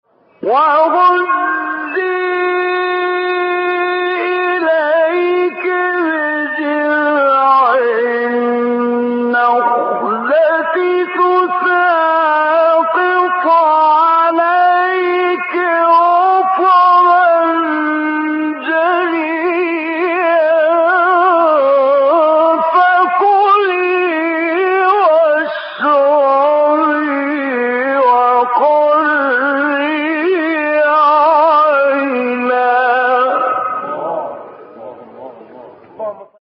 مرکب خوانی حمدی زامل سوره مریم | نغمات قرآن | دانلود تلاوت قرآن